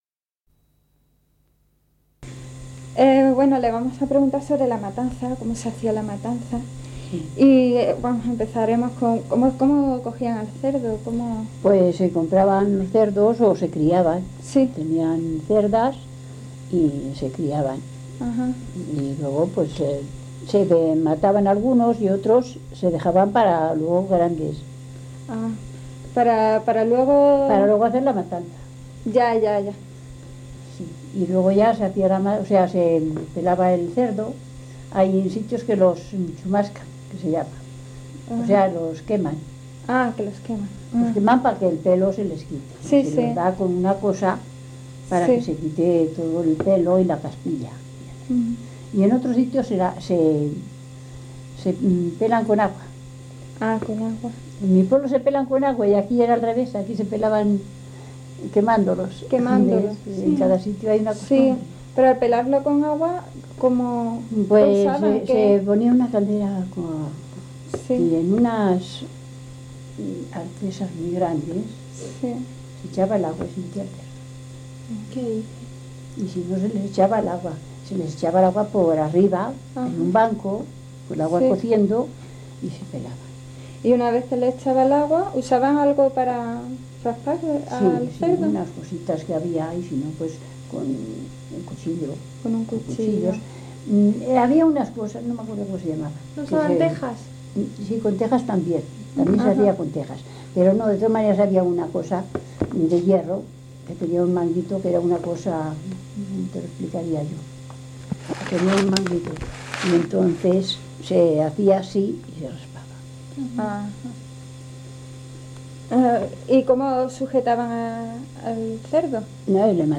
Locality Uceda